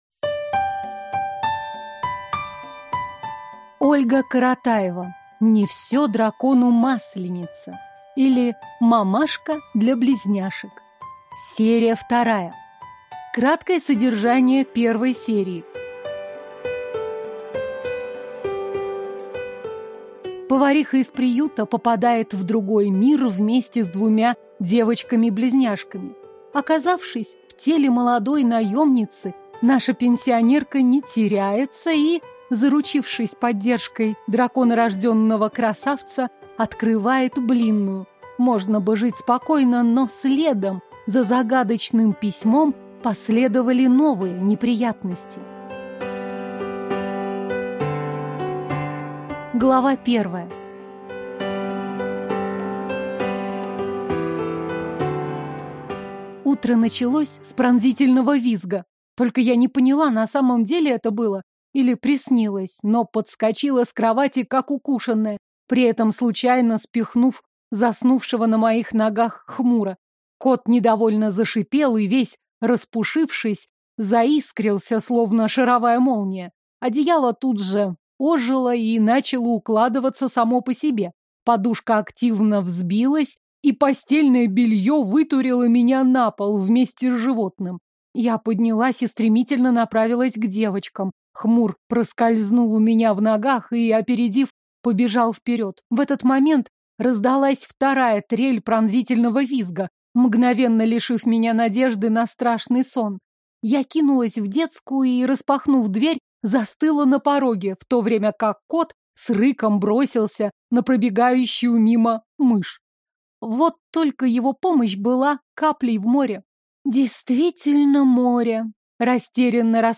Аудиокнига Не всё дракону масленица, или Мамашка для близняшек. Серия 2 | Библиотека аудиокниг